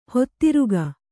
♪ hedarike